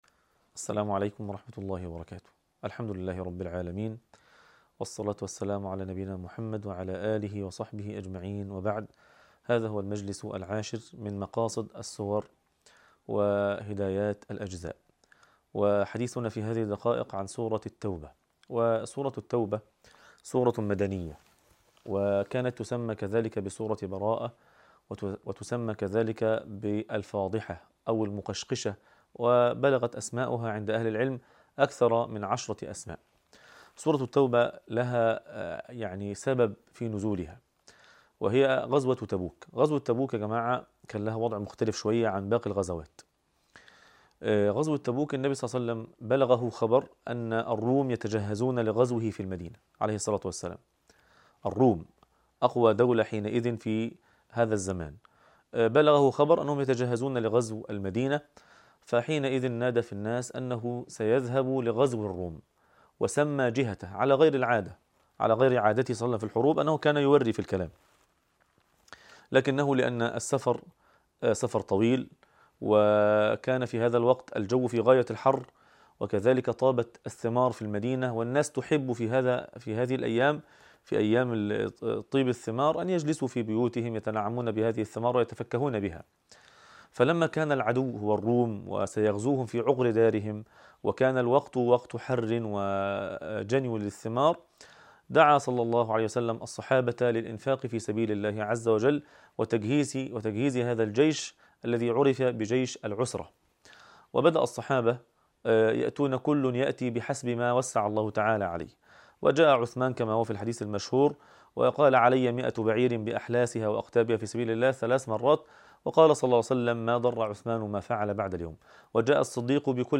عنوان المادة الدرس العاشر من مقاصد السور وهديات الأجزاء تاريخ التحميل السبت 20 سبتمبر 2025 مـ حجم المادة 16.45 ميجا بايت عدد الزيارات 82 زيارة عدد مرات الحفظ 65 مرة إستماع المادة حفظ المادة اضف تعليقك أرسل لصديق